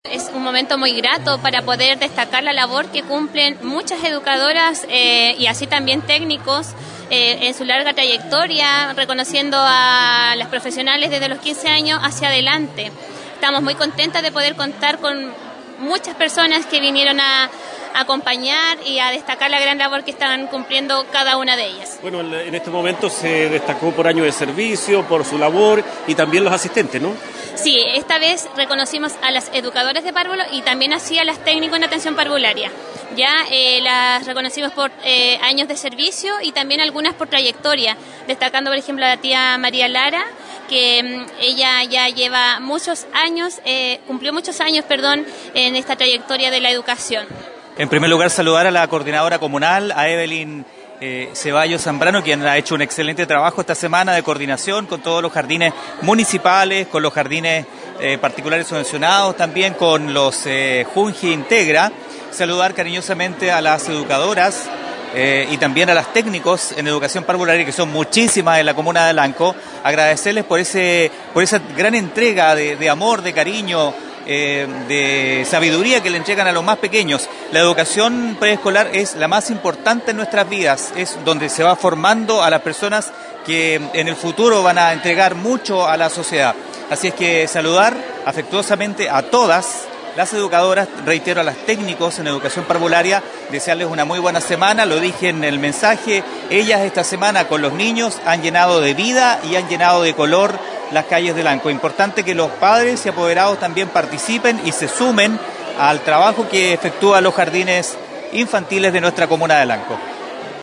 Con motivo del Día de la Educación Parvularia, se llevó a cabo un emotivo acto de reconocimiento en el Teatro Galia, organizado por el Comité Comunal de la Educación Parvularia de Lanco.
El concejal Juan Santana Paredes, en representación del municipio, enfatizó en la labor de las educadoras y sus equipos, subrayando su impacto en la formación integral de los niños en su etapa escolar inicial.